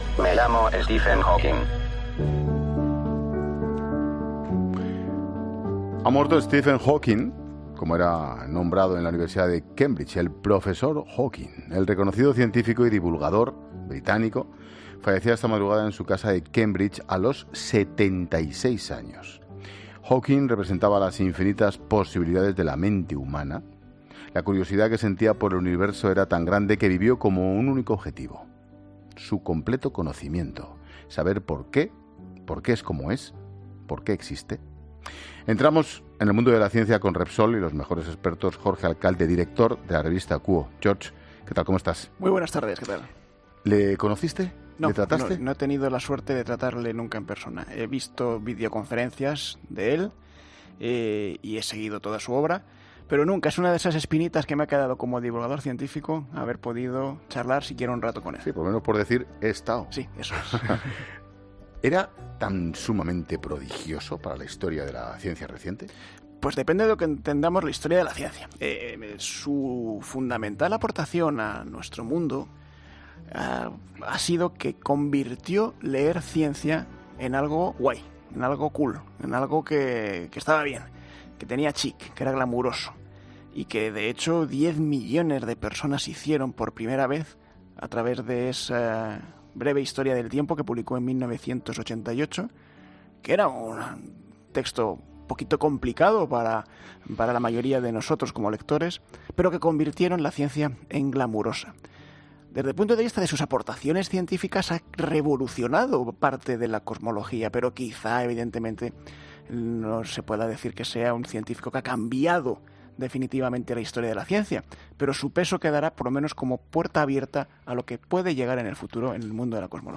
Escucha ahora la 'Ciencia' en el programa de 'La Tarde' de COPE.